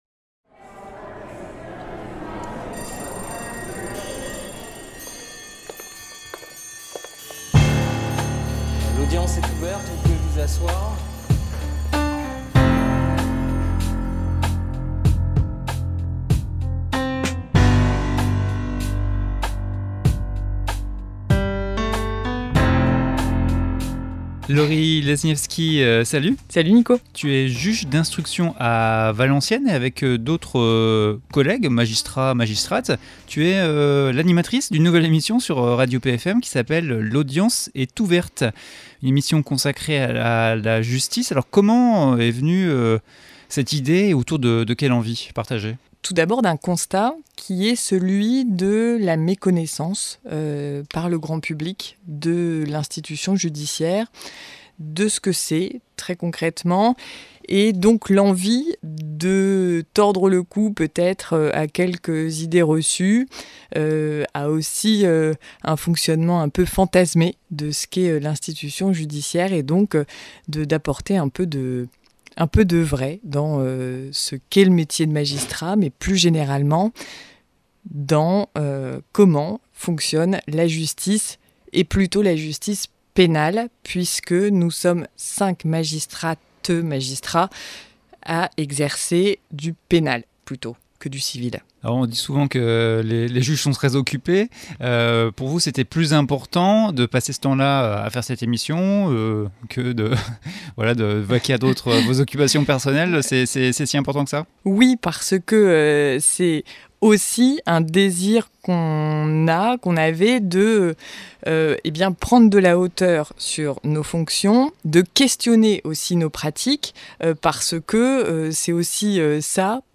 laudience-est-ouverte-interview.mp3